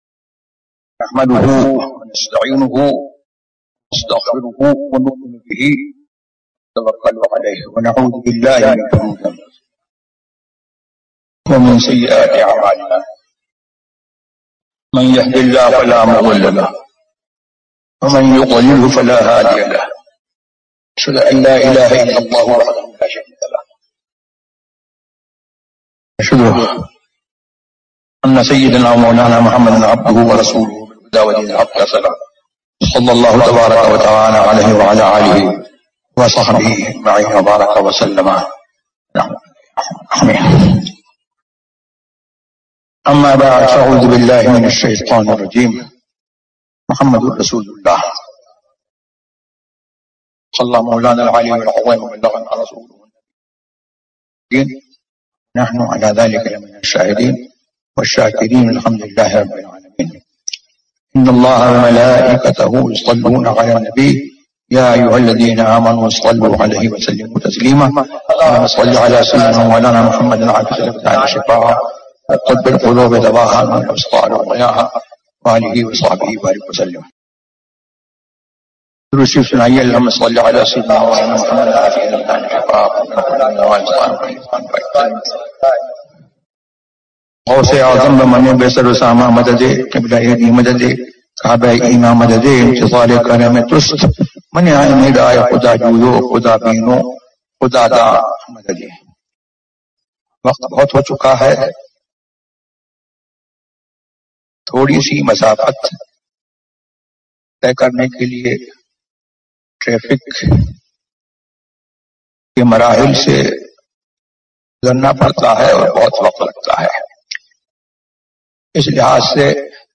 محمد رسول اللہ ZiaeTaiba Audio میڈیا کی معلومات نام محمد رسول اللہ موضوع تقاریر آواز تاج الشریعہ مفتی اختر رضا خان ازہری زبان اُردو کل نتائج 865 قسم آڈیو ڈاؤن لوڈ MP 3 ڈاؤن لوڈ MP 4 متعلقہ تجویزوآراء